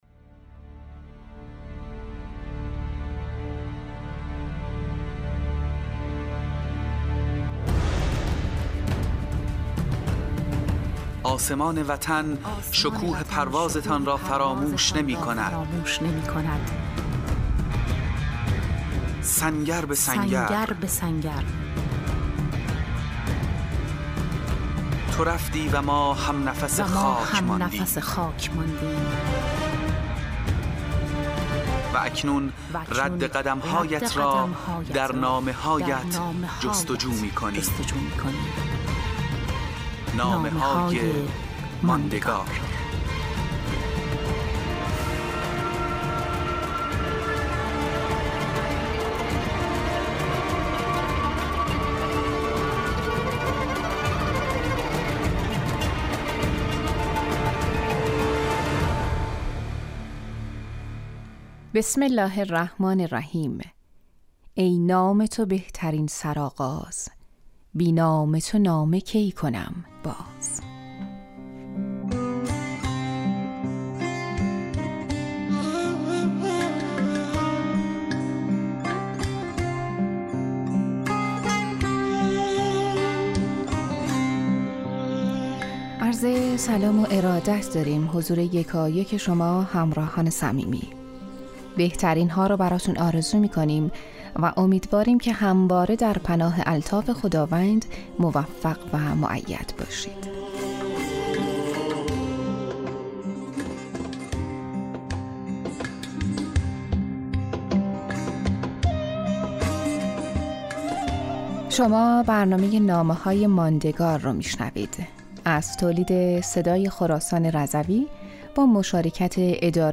برنامه رادیویی